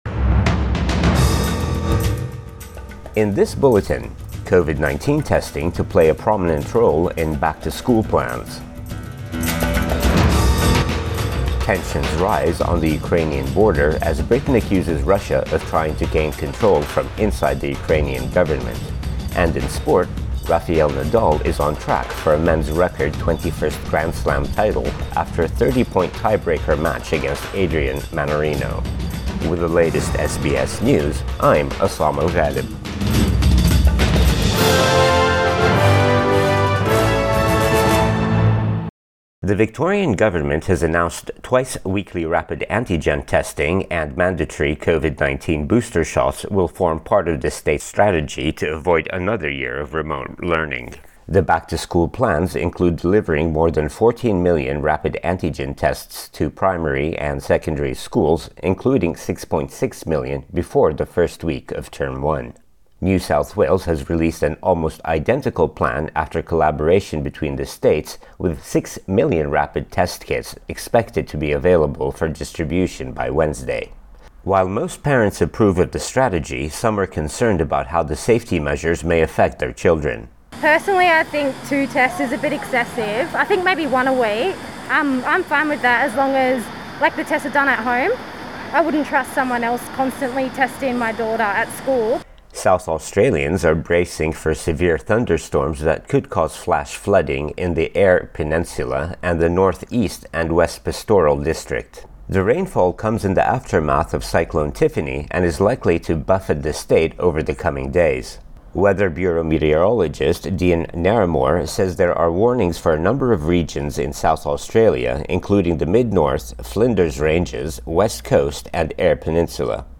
AM bulletin 24 January 2022